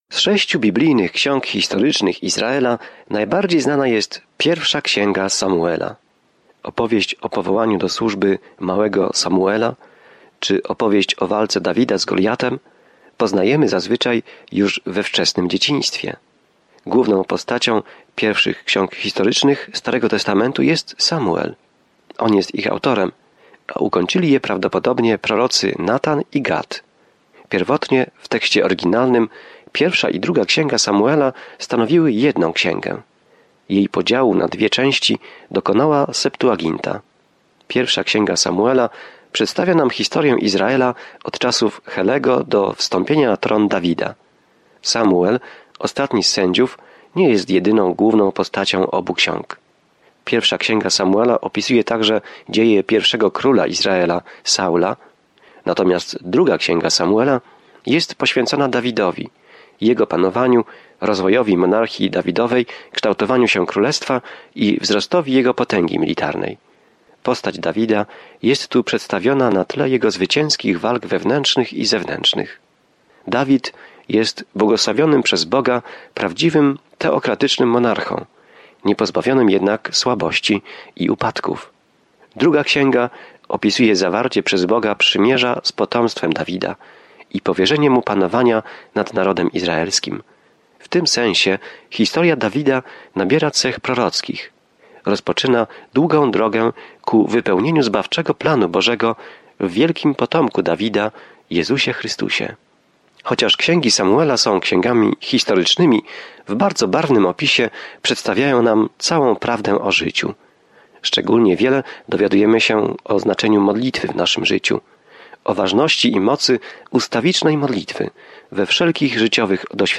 Pismo Święte 1 Samuela 1:1-20 Rozpocznij ten plan Dzień 2 O tym planie Najpierw Samuel zaczyna od Boga jako króla Izraela i kontynuuje historię o tym, jak Saul, a następnie Dawid, został królem. Codziennie podróżuj przez 1. Samuela, słuchając studium audio i czytając wybrane wersety ze słowa Bożego.